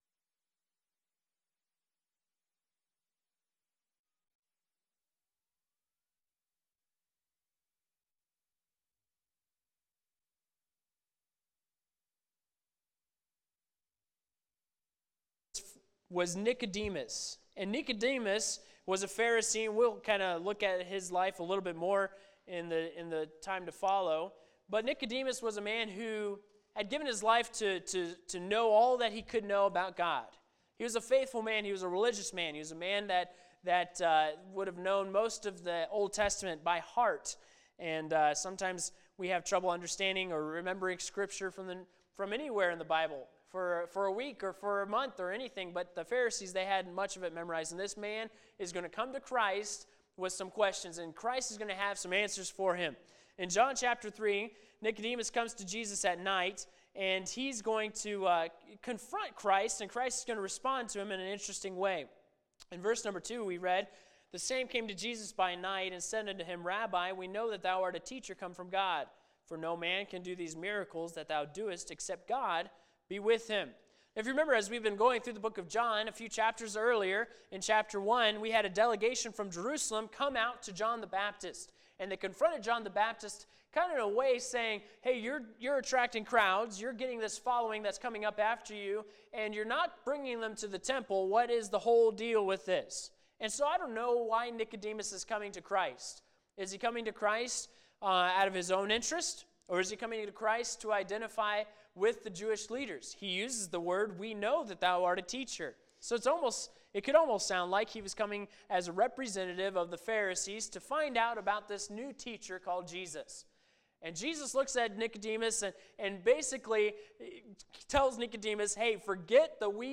The sermon discusses the biblical story of Nicodemus, a Pharisee who sought knowledge from Jesus. The speaker highlights the story of Moses lifting up a serpent as a symbol of sin and the need for salvation, comparing it to Jesus' crucifixion.